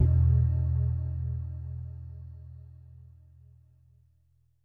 LEAD E1.wav